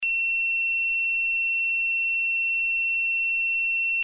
ZUMBADOR - SONIDO CONTINUO/INTERMITENTE
Zumbador Electrónico Empotrable para cuadro ø 22,5 MM.
dB 80
111_continuo
111_continuo.mp3